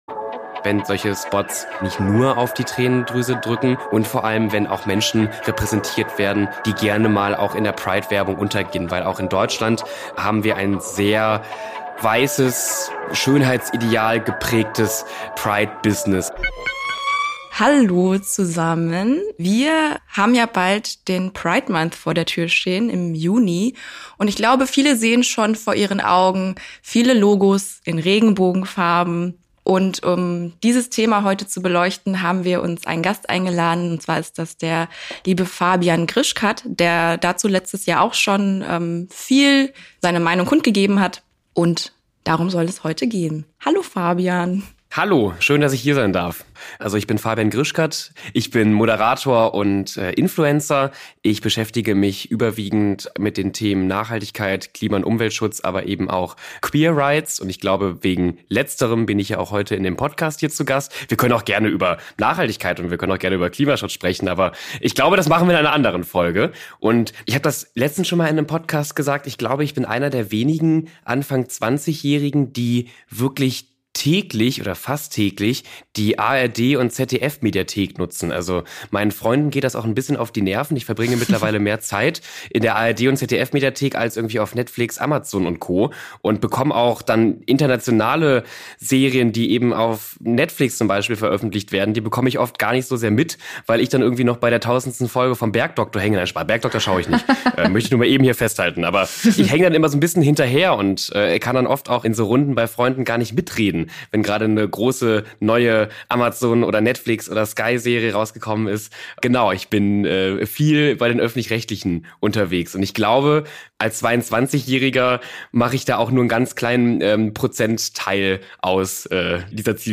interviewt. Er erklärt, was die letzten Jahre schief gelaufen ist, aber natürlich vor allem, was Marken dieses Jahr wertvolles zum Pride Month beitragen können und an welchen Vorbildern man sich dabei orientieren kann.